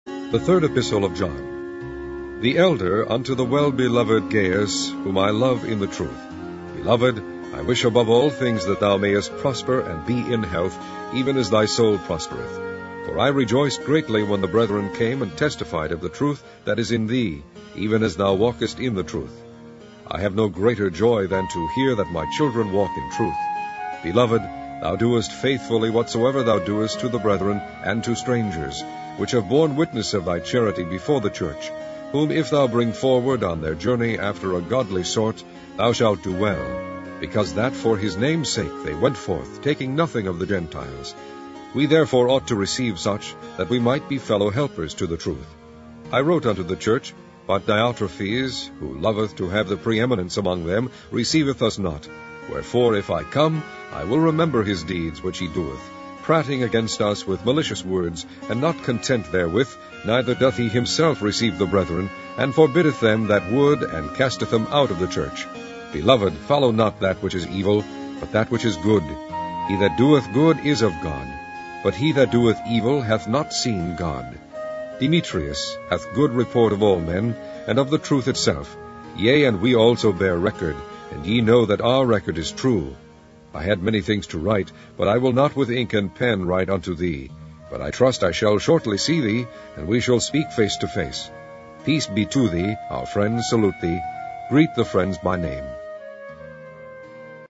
Primitive Baptist Digital Library - Online Audio Bible - King James Version - 3 John